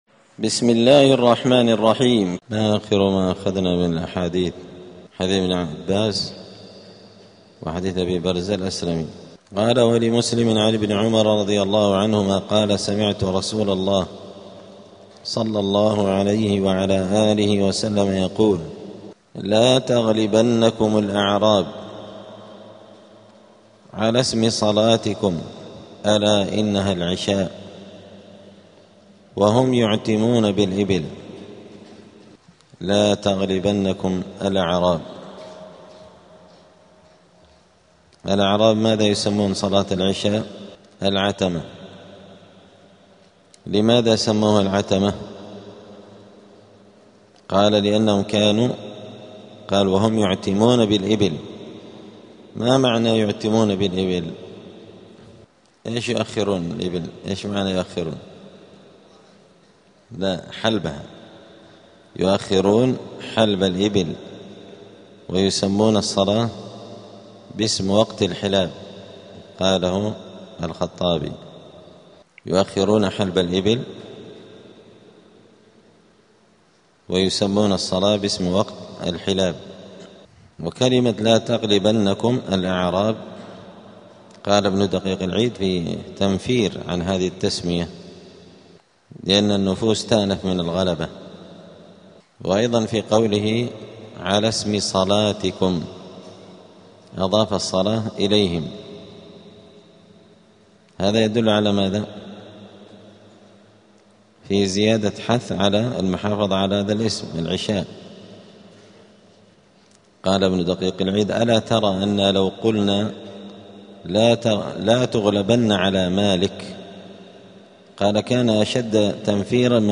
دار الحديث السلفية بمسجد الفرقان قشن المهرة اليمن
*الدرس الثامن والثلاثون بعد المائة [138] {تسمية صلاة العشاء بالعتمه}*